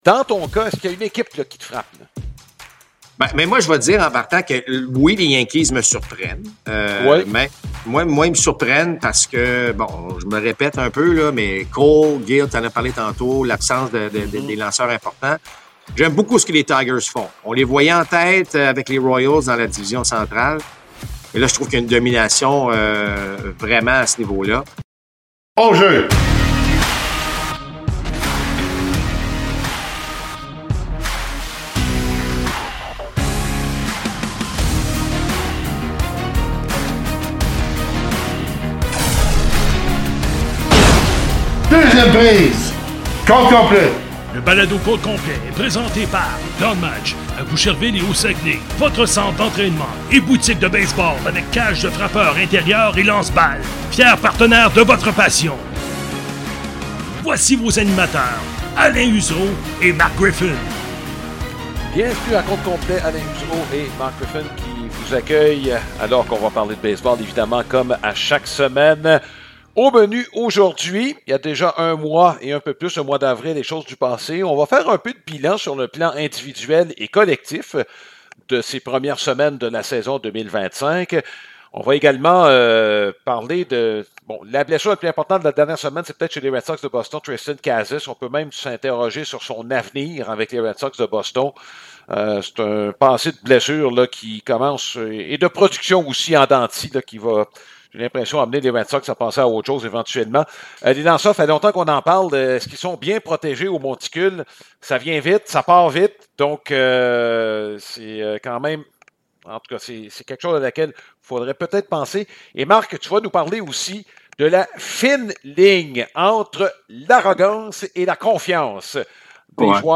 Compte complet présente des entretiens avec des personnalités reliées au baseball.